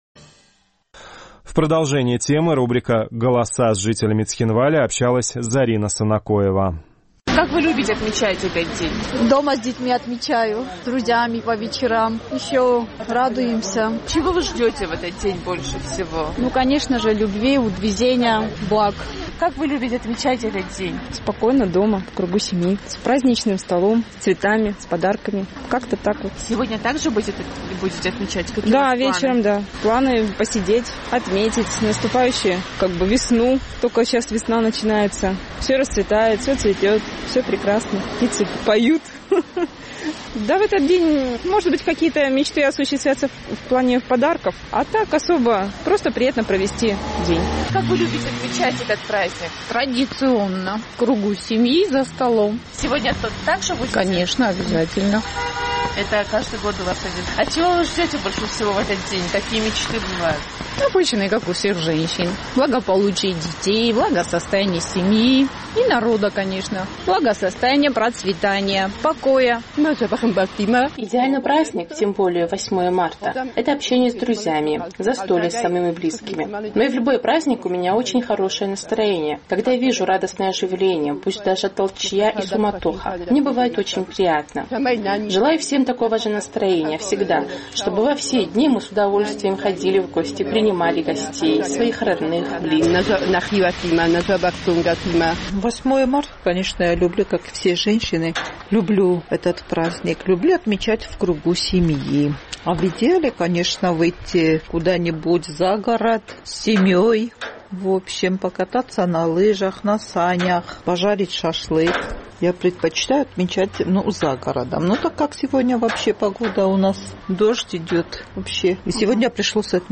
Наш югоосетинский корреспондент поинтересовалась у местных жителей, как и с кем они отмечают 8 марта.